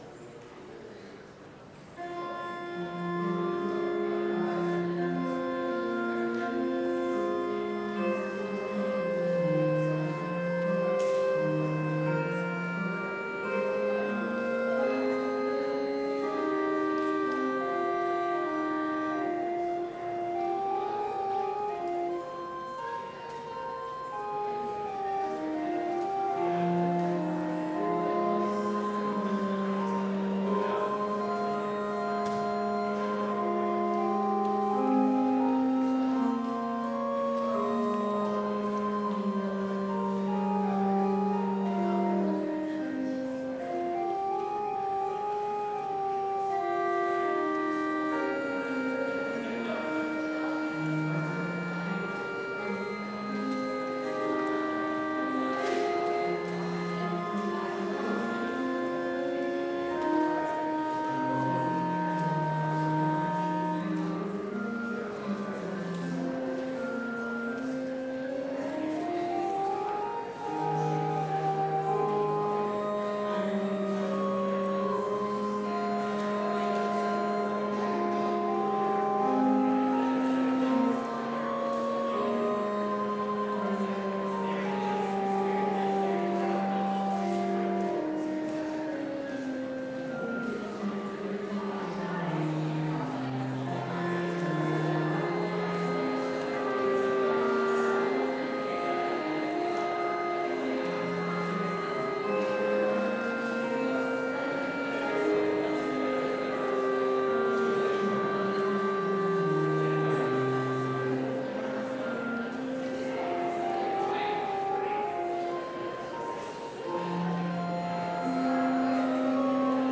Complete service audio for Chapel - January 22, 2020